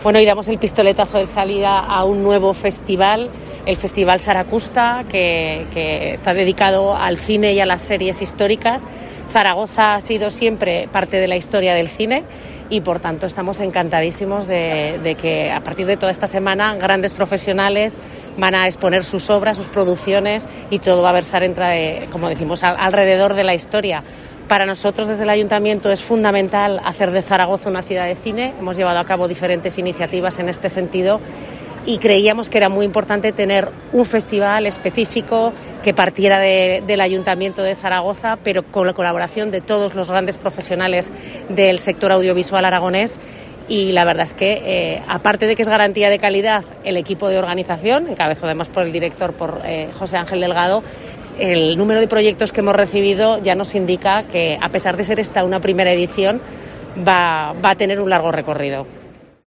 La vicealcaldesa de Zaragoza, Sara Fernández, presenta el Saraqusta Film Festival 2021.